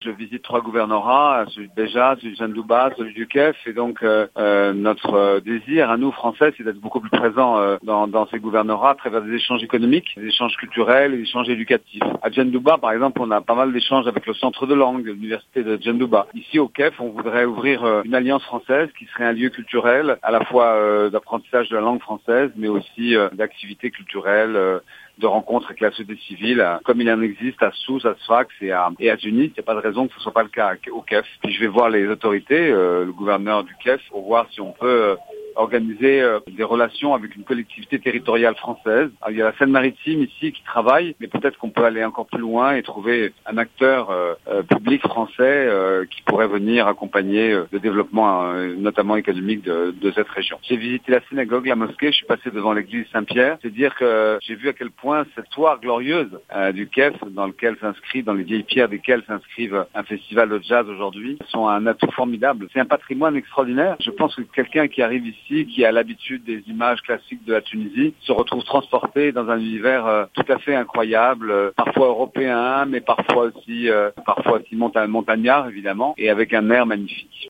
قال السفير الفرنسي في تونس، أوليفيي بوافر دارفور، في تصريح لمراسل الجوهرة أف أم، على هاش زيارة أداها اليوم إلى ولاية الكاف، إن هناك مساعي لبعث مركز ثقافي فرنسي في الكاف مختص في تعليم اللغة الفرنسية بالإضافة إلى تنظيم أنشطة ثقافية متنوعة، كما هو الحال في تونس وصفاقس وسوسة.